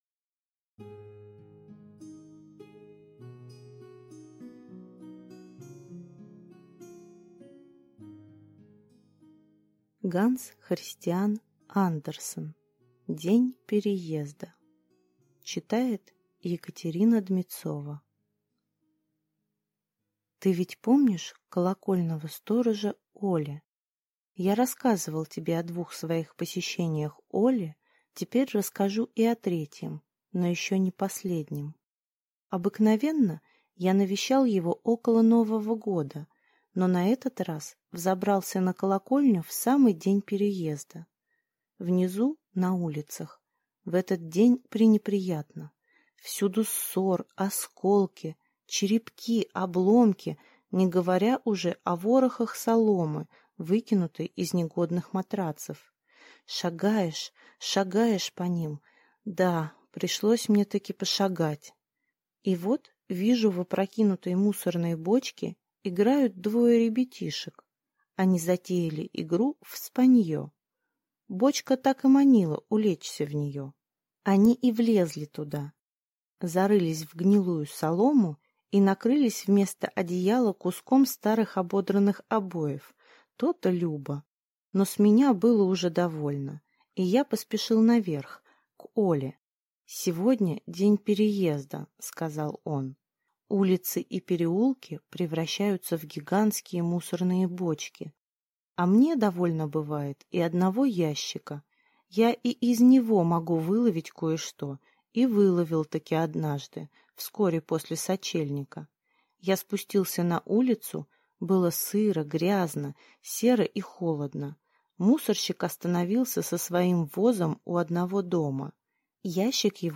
Аудиокнига День переезда | Библиотека аудиокниг